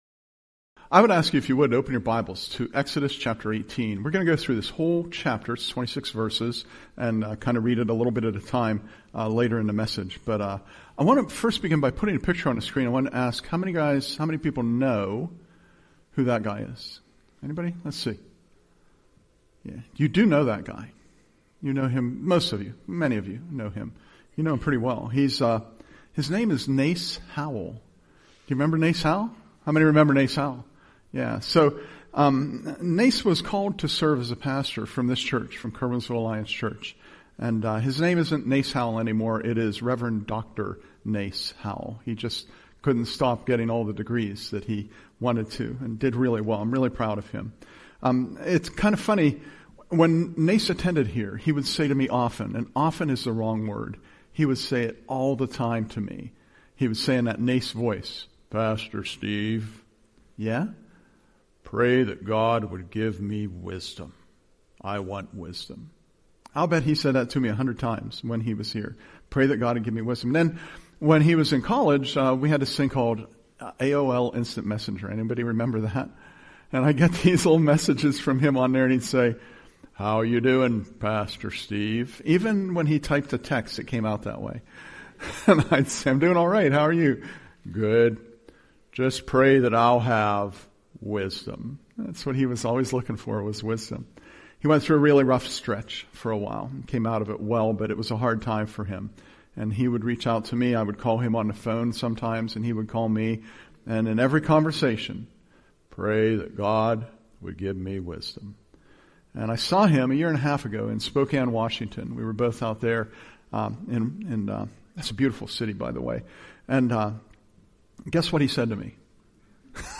Presented at Curwensville Alliance